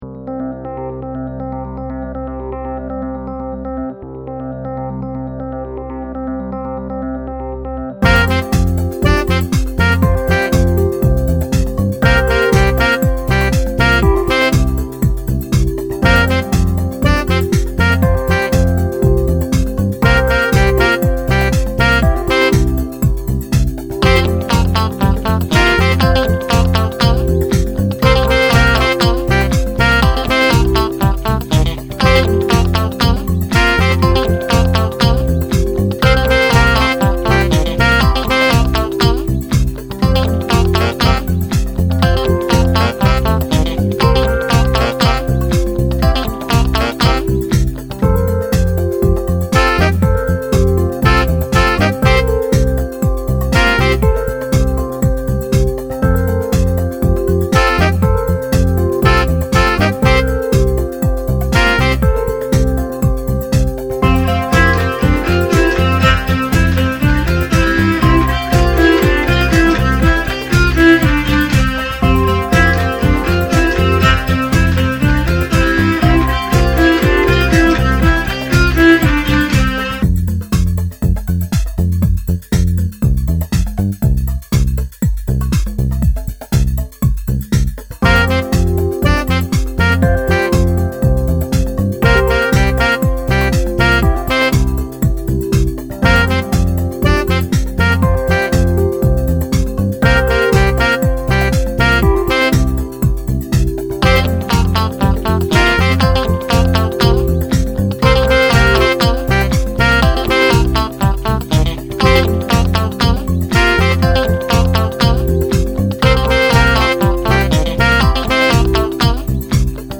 アップルループという細切れされた音楽の断片を画面上で組み合わせて音楽ができるのですが、これがあっけないくらいサクサク作れてしまうのです。